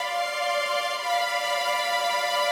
GS_Viols_95-D2.wav